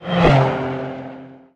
car6.ogg